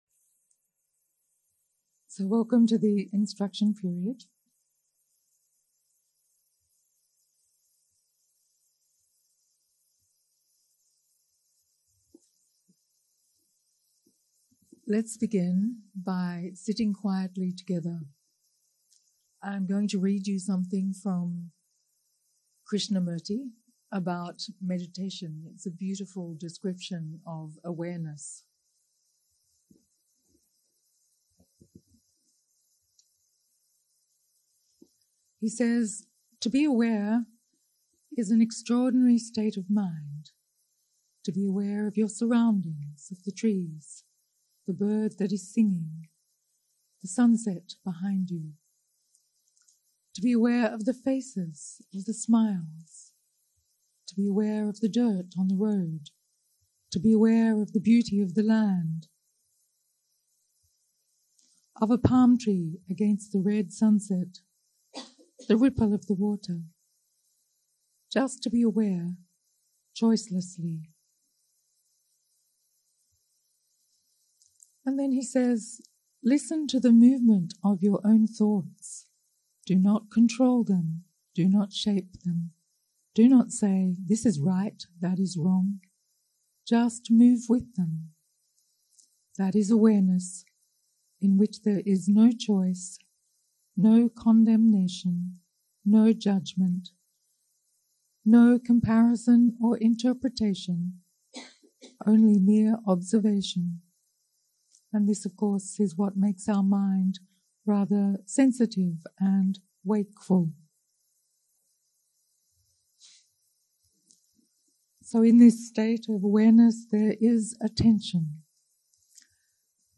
יום 4 - הקלטה 8 - בוקר - הנחיות למדיטציה - אנאפאנהסטי סוטה קטגוריה ד (תופעות) 0:00 0:00 סוג ההקלטה: Dharma type: Guided meditation שפת ההקלטה: Dharma talk language: English